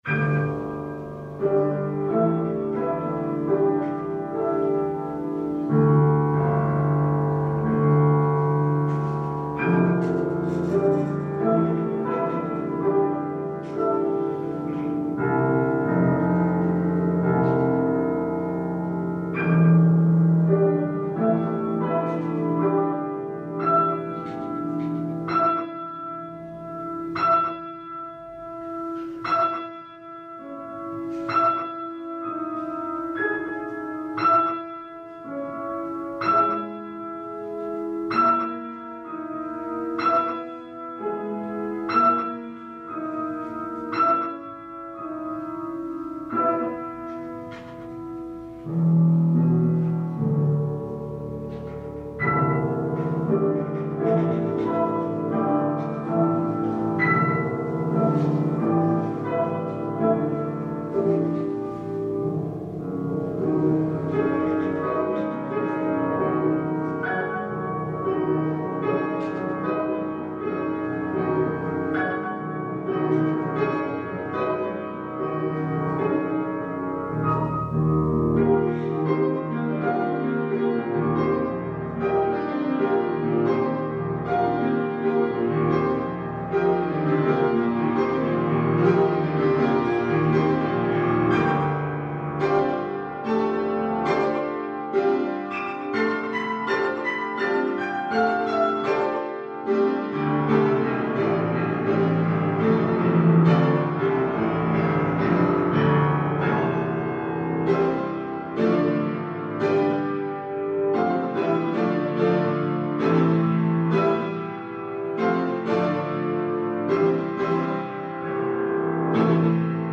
zongora